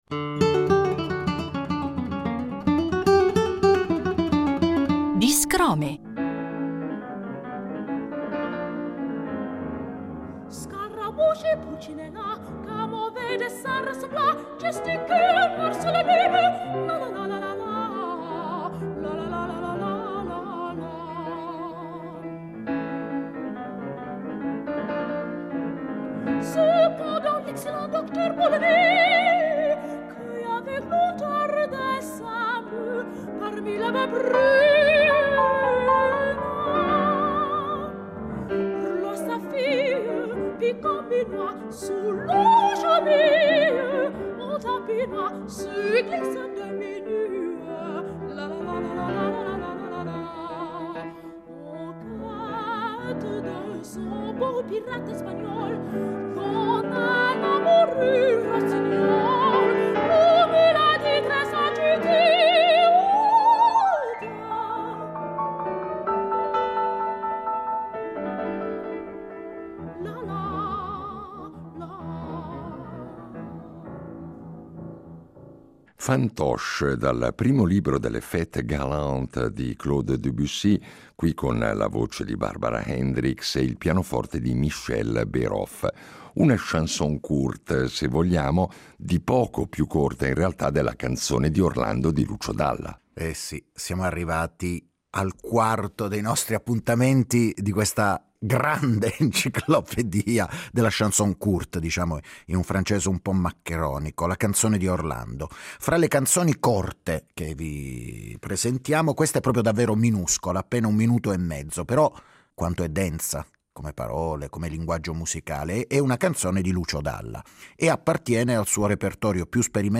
Vi facciamo ascoltare queste canzoni, in versione discografica o live, raccontandovi qualcosa dei loro autori e del contesto in cui nacquero… poesia, musica e storie in cinque minuti: un buon affare!